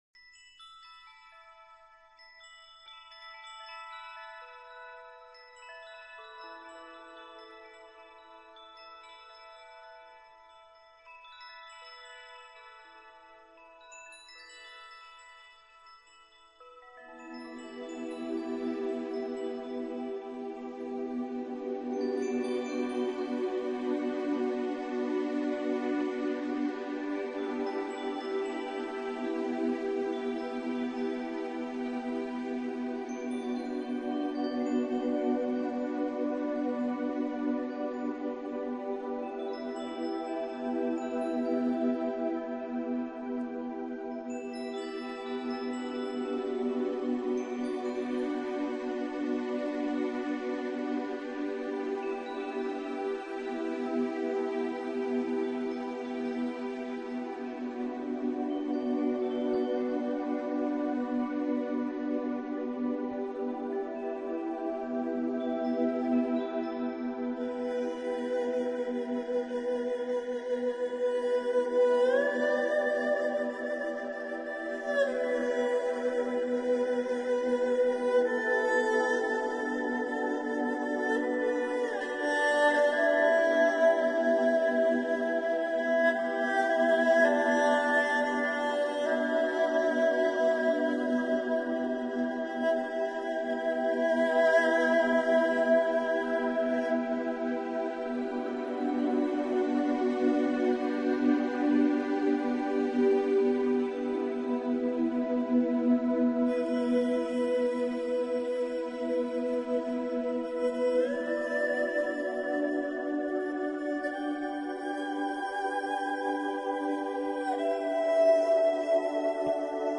Relaxation – Musique relaxante: Calme, Douce Fréquences favorisant paix et détente Bruit des Vagues Douces & Musique Relaxante pour Dormir.
Fréquences-favorisant-paix-et-détente.mp3